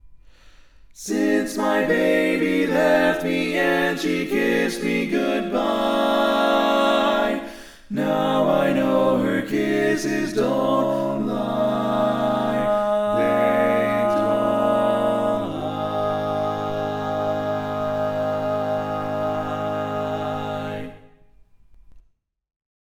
Type: Barbershop